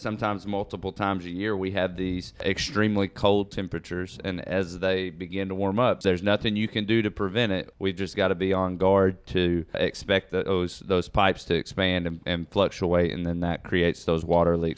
They say there’s nothing that can be done to stop water pipe leaks when we go from a cold snap to warmer temperatures.  Here’s Deputy City Administrator Brad Long.